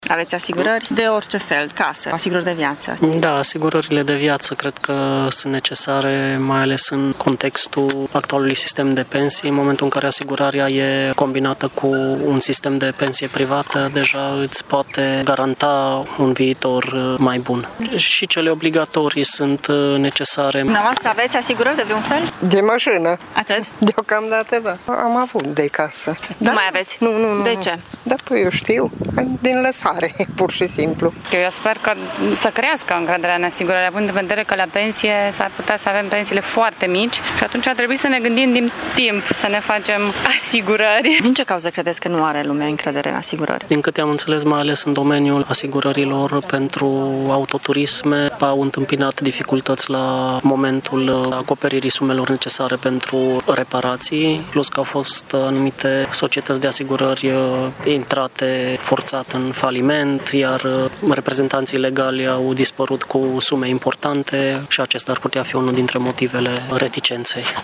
Târgumureșenii sunt conștienți de necesitatea asigurărilor, atât cele obligatorii cât și cele facultative, însă mulți nu își fac asigurări deoarece nu mai au încredere: